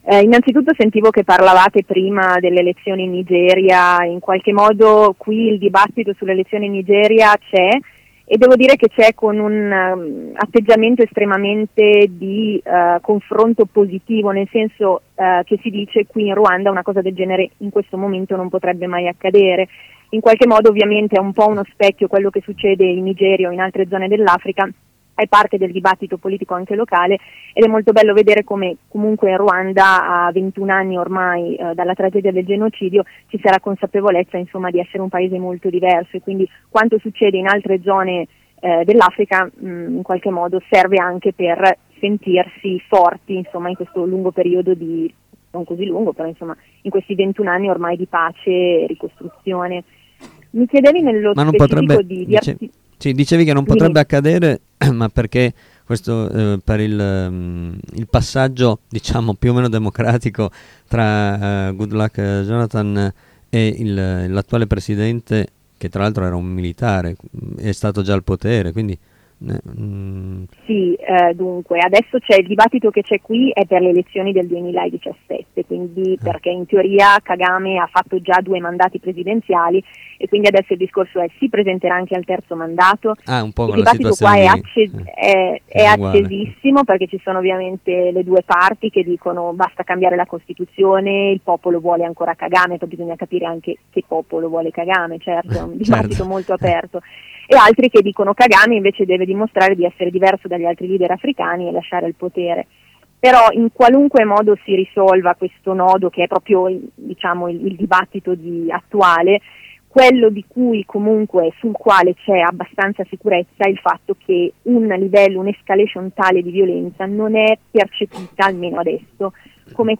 Per legge il 30% di donne deve partecipare agli organismi politici, la violenza di genere è punita severamente e le donne possono ereditare la terra, cosa che non è in molti altri paesi africani. Questa dinamica vede però alcune notevoli differenze tra città e campagna. Ne abbiamo parlato in collegamento diretto da Kigali